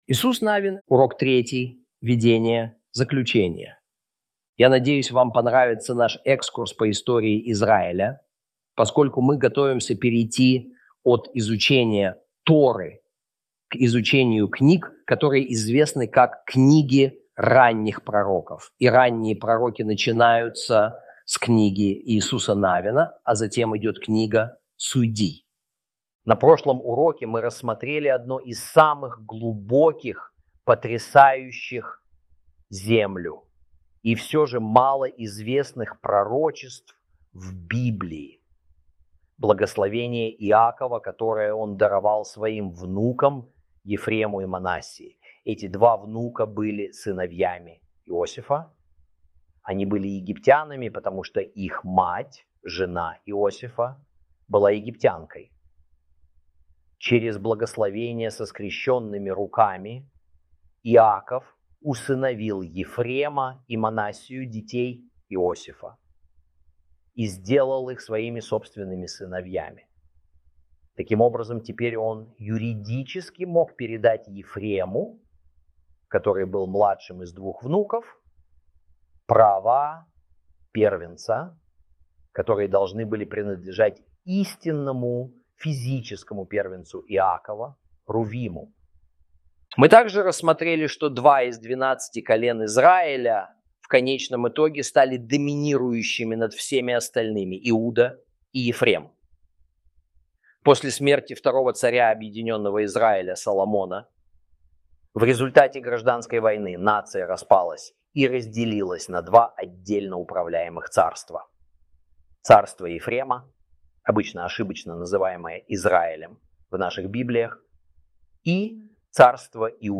Урок 3 Введение - Кни́га Иису́са Нави́на - Torah Class